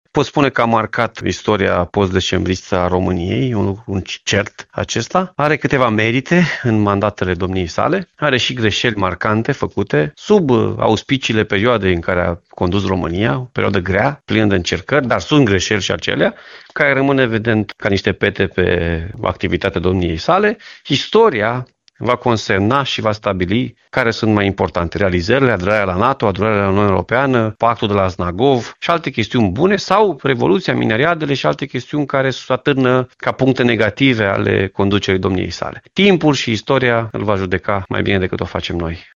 La rândul său, președintele PSD Timiș și al Consiliului Județean Timiș, Alfred Simonis, a declarat pentru Radio Timișoara că nu se poate pronunța asupra modului în care Ion Iliescu va rămâne în istorie.
02-Alfred-Simonis-Iliescu-20.mp3